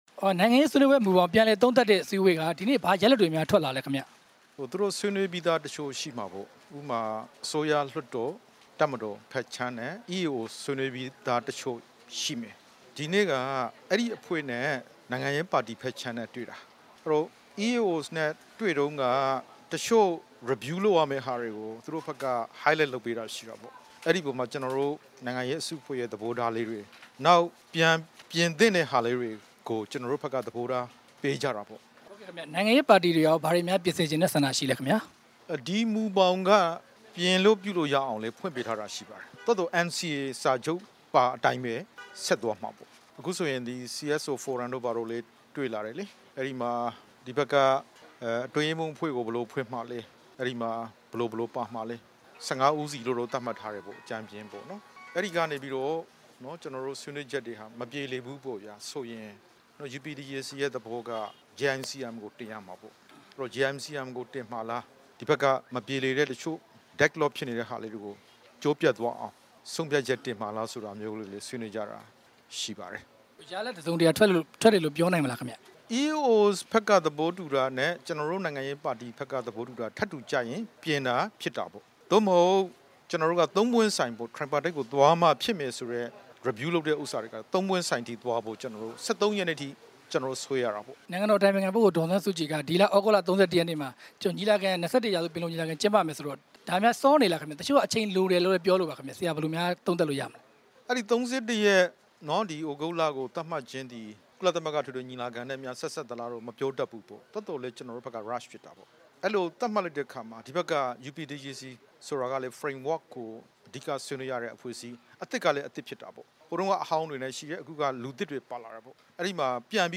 ဒေါက်တာ အေးမောင် ကို တွေ့ဆုံမေးမြန်းချက်